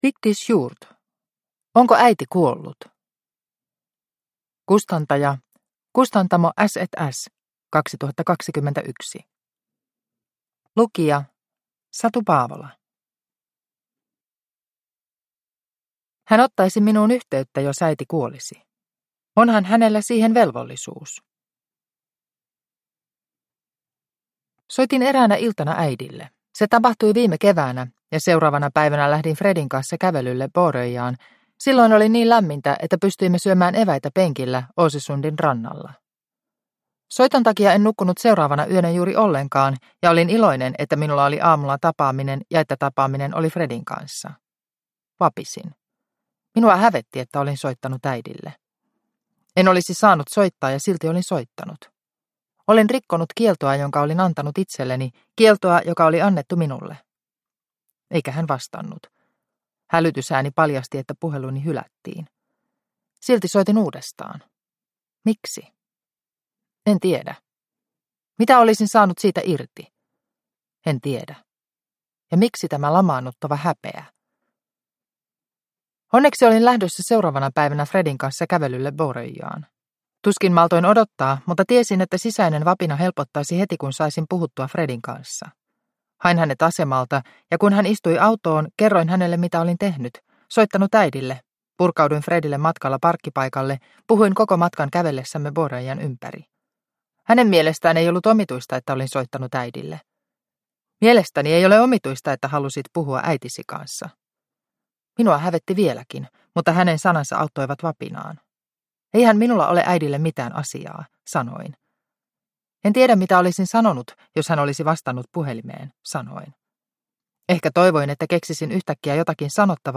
Onko äiti kuollut – Ljudbok – Laddas ner